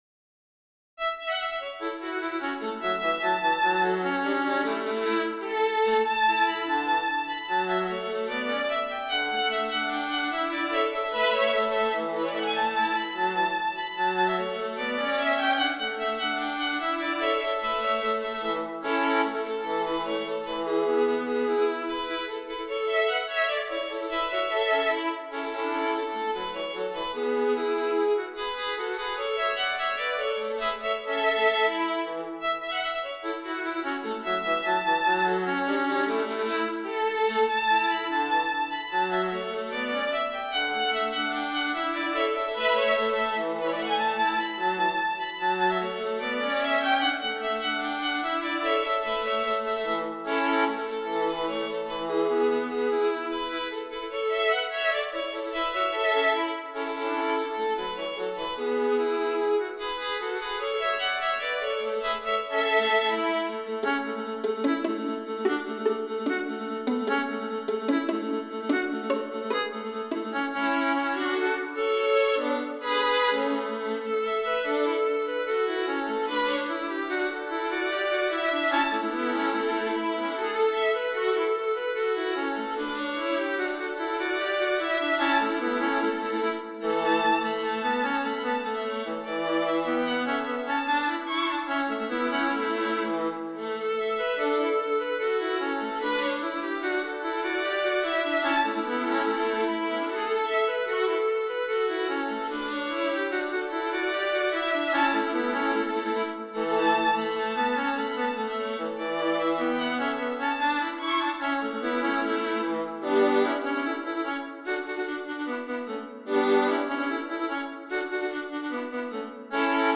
With antecedent versions from England in previous centuries, this jolly fiddle tune has a number of variants recorded in a variety of players from solo to small ensemble.
2 pages, circa 2' 15" - an MP3 demo is here: